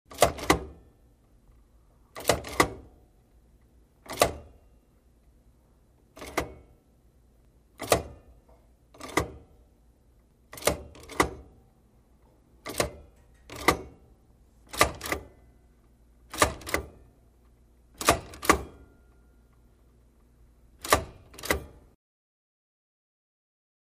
LaundromatLoopGene PE967501
Laundromat Loop, General Ambience